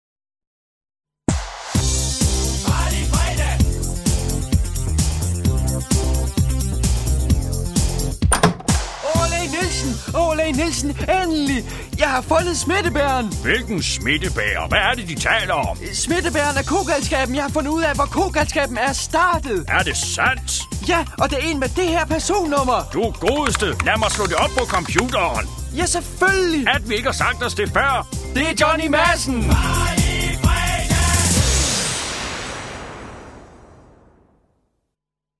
Det er her, du kan høre alle de gode, gamle indslag fra ANR's legendariske satireprogram.